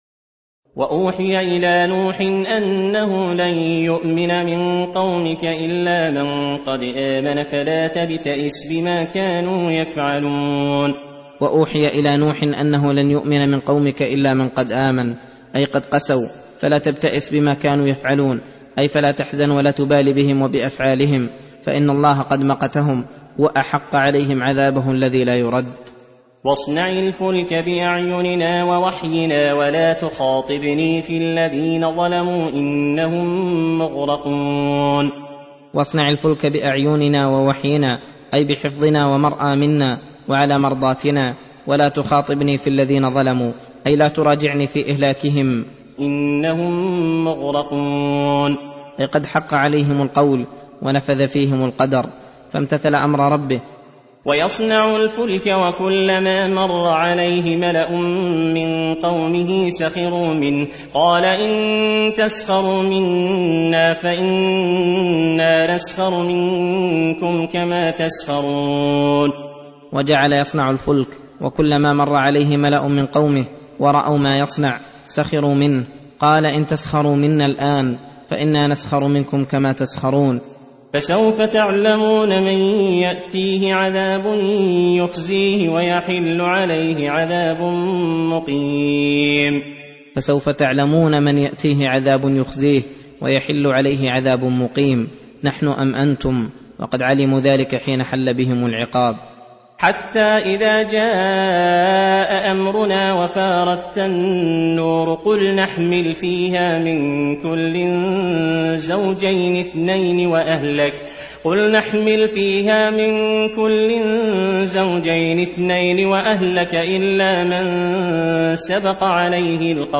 درس (33) : تفسير سورة هود: (36- 60)